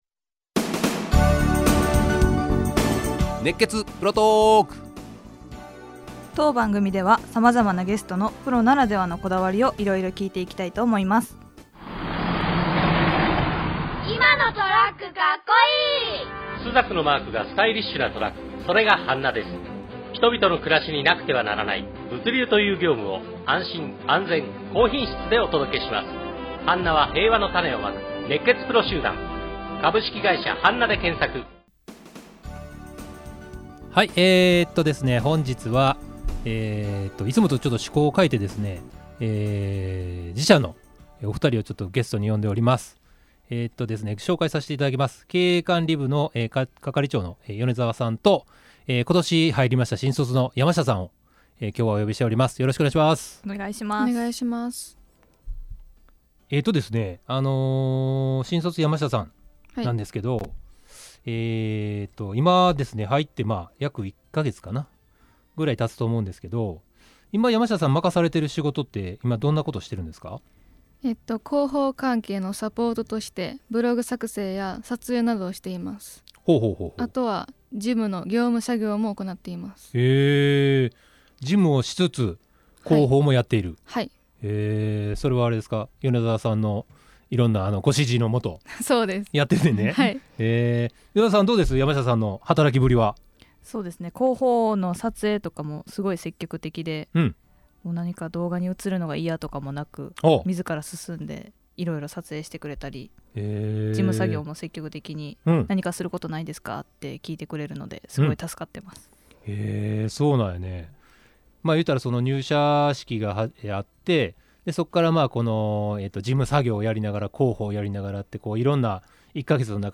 【FMラジオ収録】
本日FMまほろば放送！
ハンナ広報のことや、新卒者の紹介を主に話しました！！ 会話が盛り上がり、楽しかったです✨ 下記から是非お聴きください！